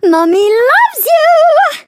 flea_ulti_vo_06.ogg